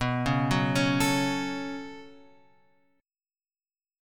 B 7th Suspended 2nd